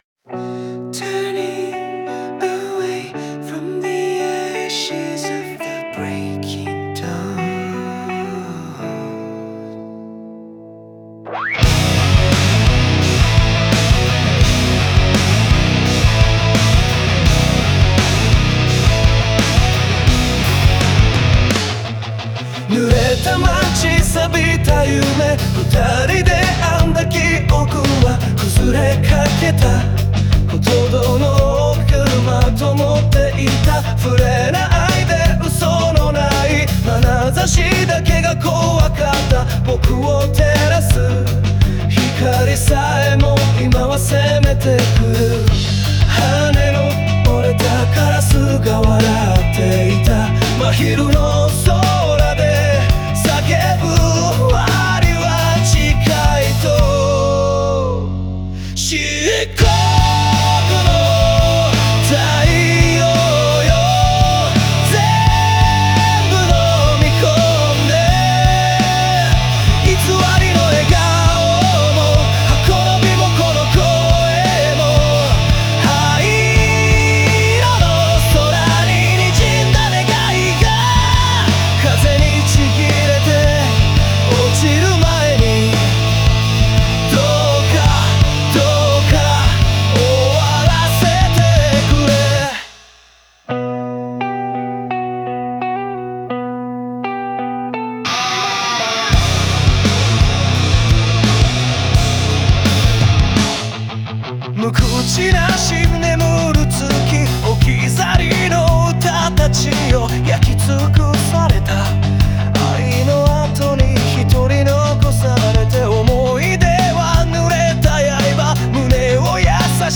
静けさと激しさが交錯する音の波に乗せ、癒されることのない痛みと希望が同時に歌われている。